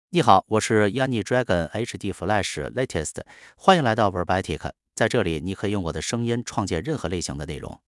Yunye Dragon HDFlash LatestMale Chinese AI voice
Yunye Dragon HDFlash Latest is a male AI voice for Chinese (Mandarin, Simplified).
Voice sample
Male